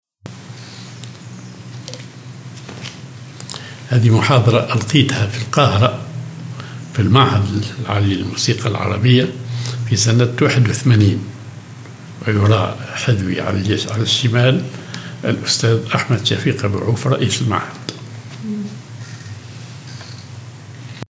محاضرة في معهد الموسيقى العربية في القاهرة